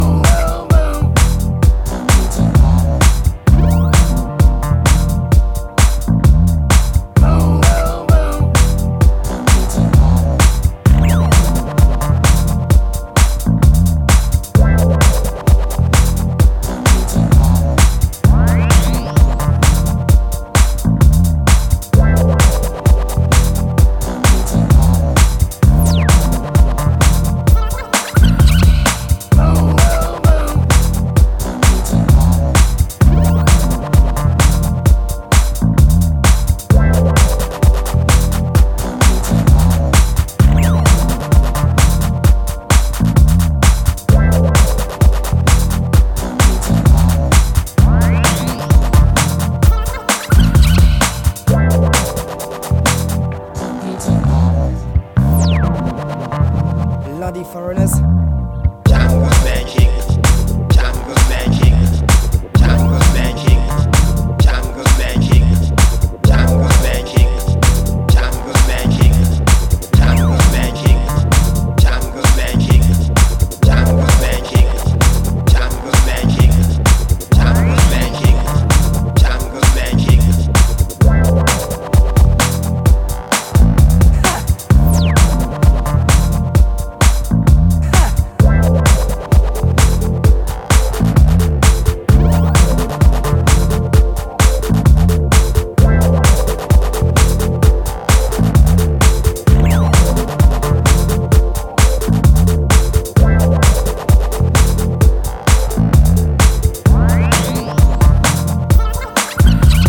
the b-boy mood